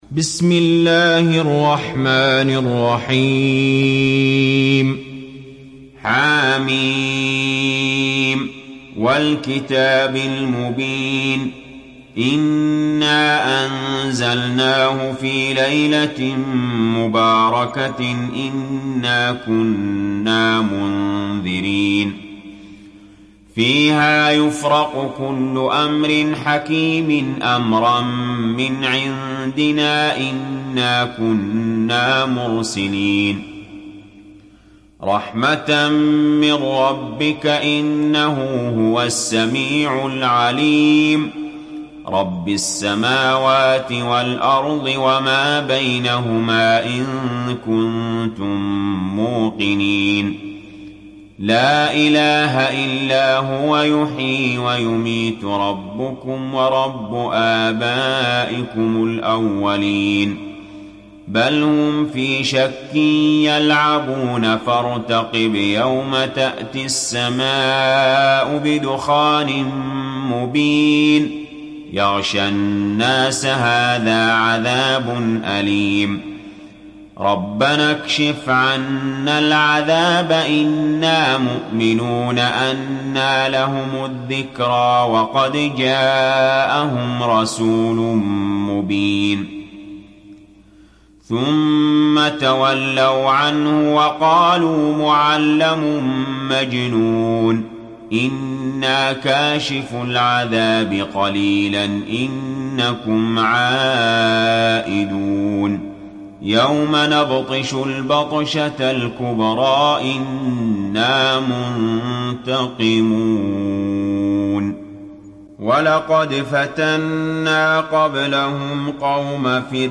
دانلود سوره الدخان mp3 علي جابر روایت حفص از عاصم, قرآن را دانلود کنید و گوش کن mp3 ، لینک مستقیم کامل